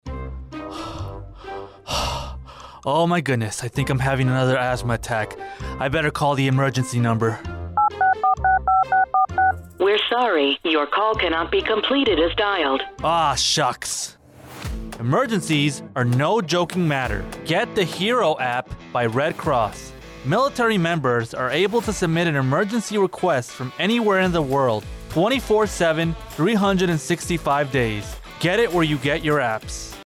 AFN Naples Radio Spot - Red Cross Hero Care App